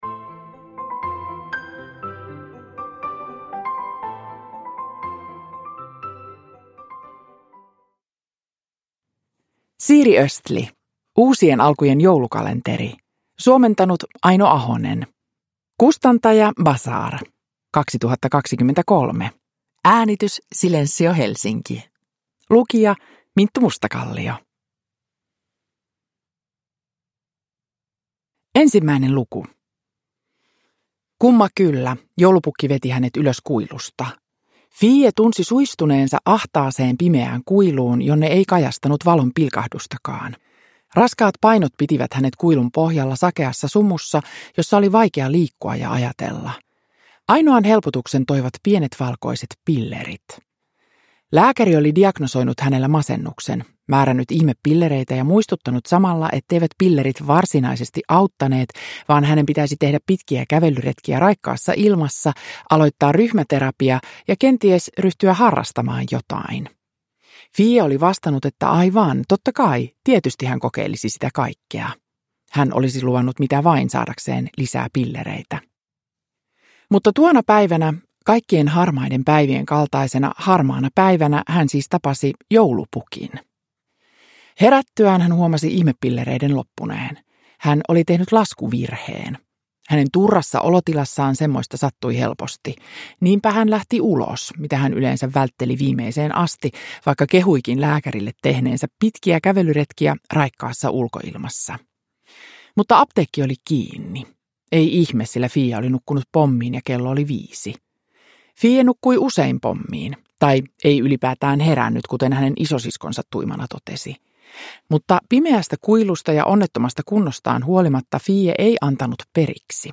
Uusien alkujen joulukalenteri – Ljudbok – Laddas ner
Uppläsare: Minttu Mustakallio